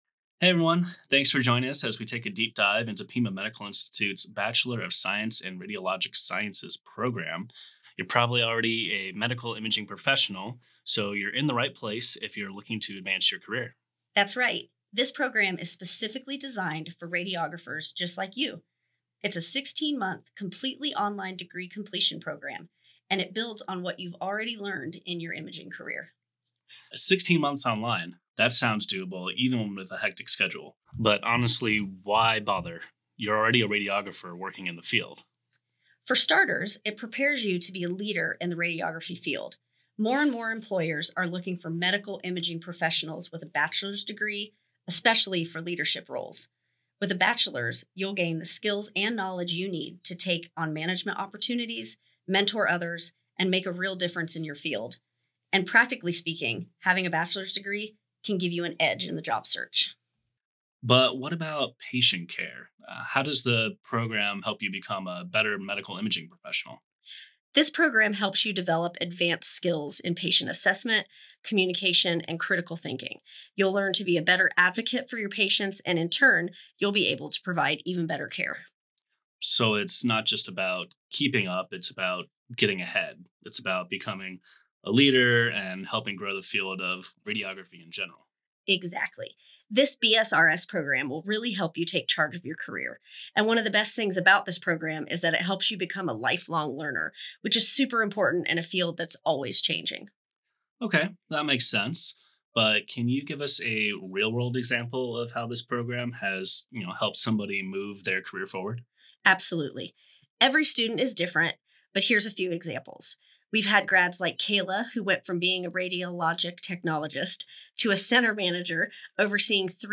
Pima_Medical_Institute-BSRS_Podcast-2025.mp3